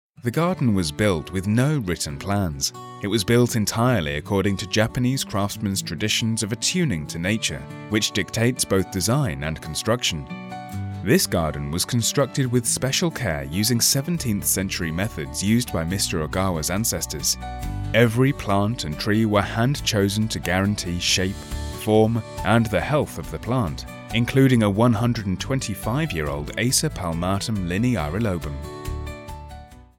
English (British)
Narration
Custom-built home studio